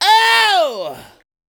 PNCINTLOFWGKTA Oww.wav